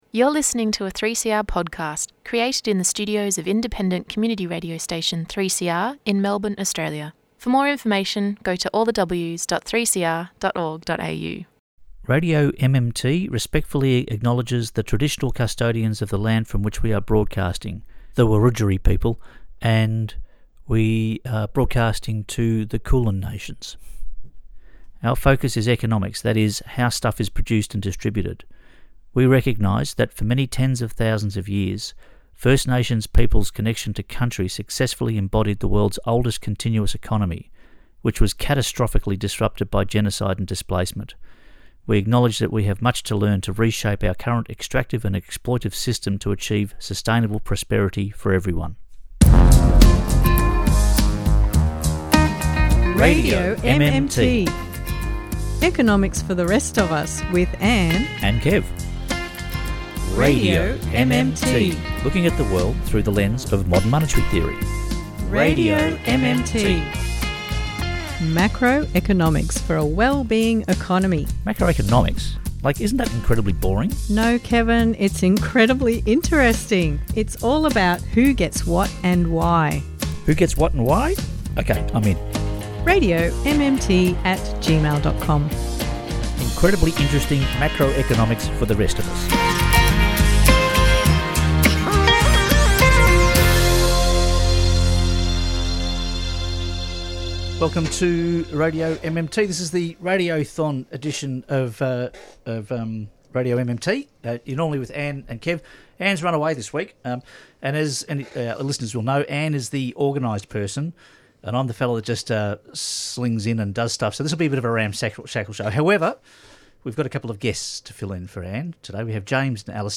3CR Community Radio